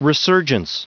Prononciation du mot resurgence en anglais (fichier audio)
Prononciation du mot : resurgence